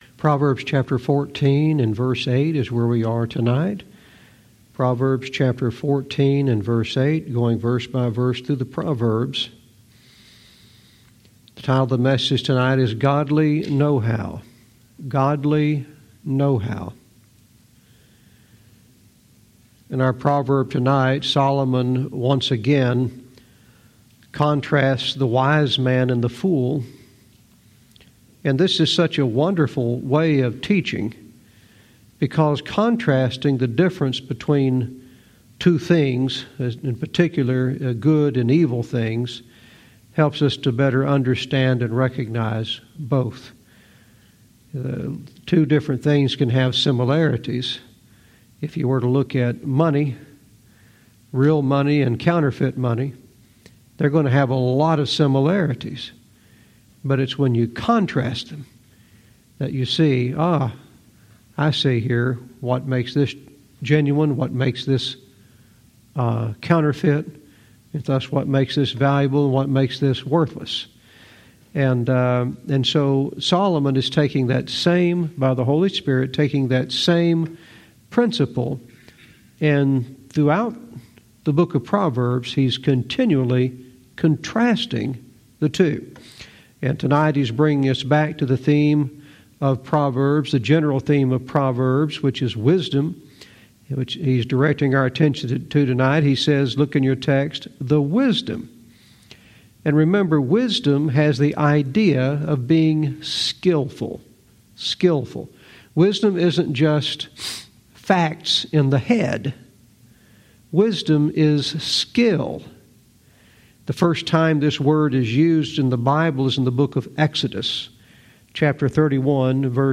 Verse by verse teaching - Proverbs 14:8 "Godly Know-How"